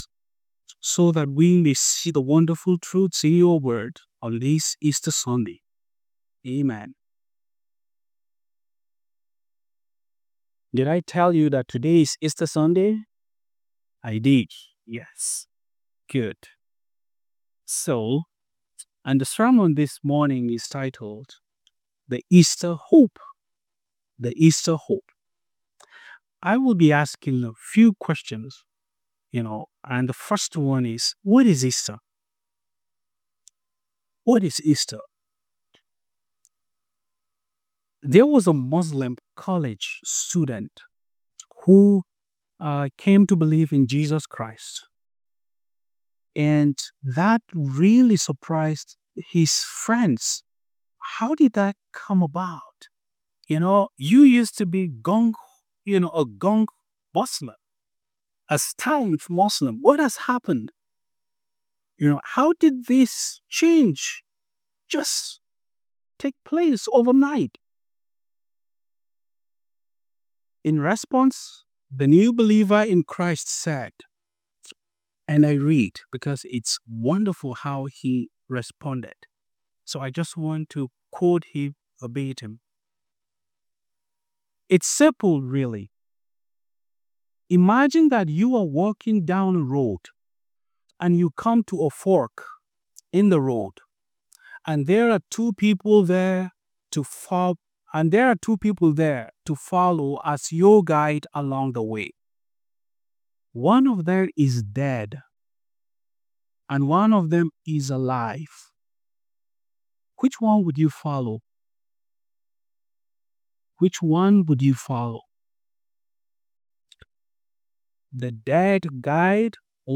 A Daily Bible Devotional (ODB)